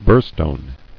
[buhr·stone]